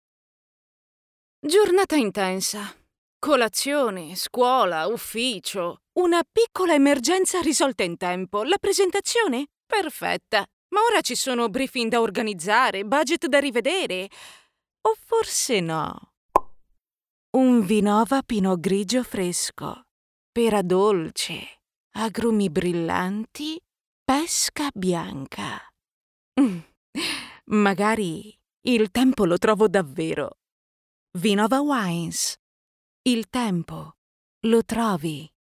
Professional voice actress with a fresh, dynamic and extremely versatile voice.
Sprechprobe: Sonstiges (Muttersprache):